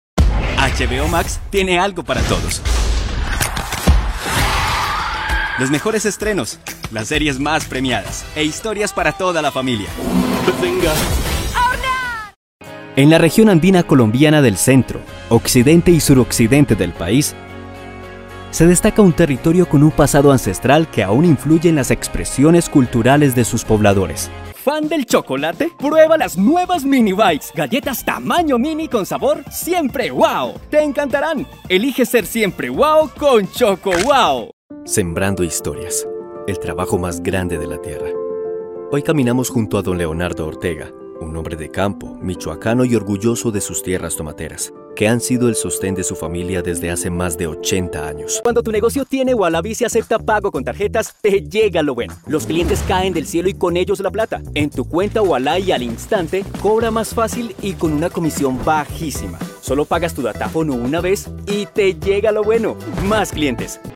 Espagnol (Colombien)
De la conversation
Convaincant
Amical